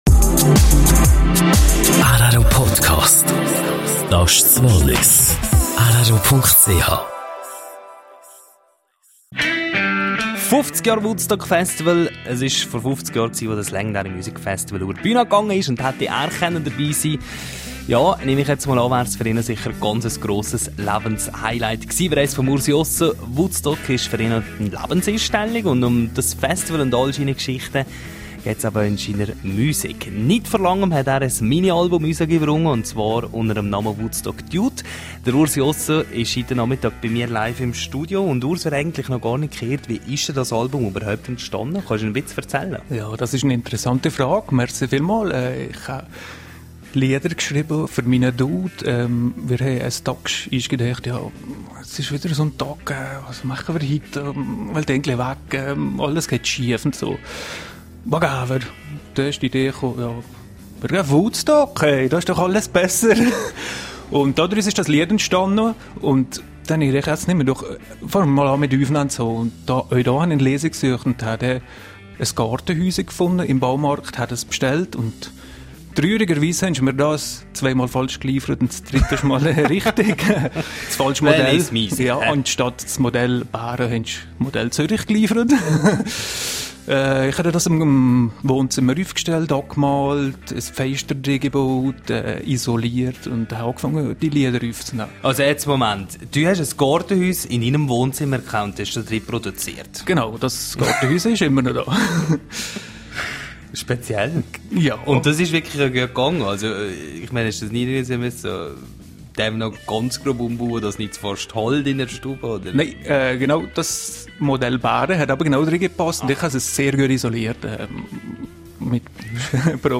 Oberwallis: Woodstock zu Besuch im Radiostudio
Im Interview sprach er über seine Musik und über seine Faszination zu Woodstock.